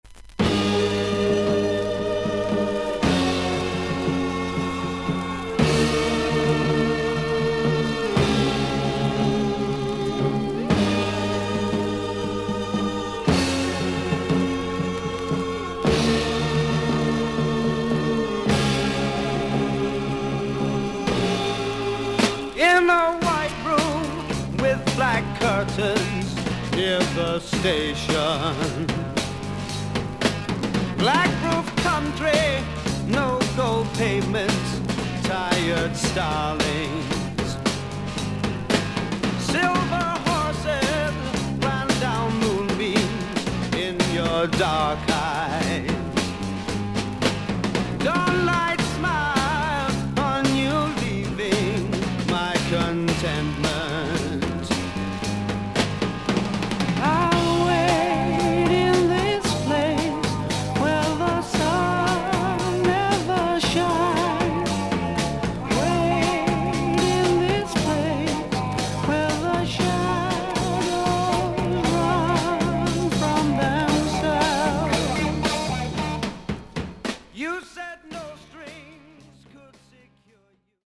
少々軽いパチノイズの箇所あり。少々サーフィス・ノイズあり。クリアな音です。
スタジオ録音とライヴを収録したLP2枚組。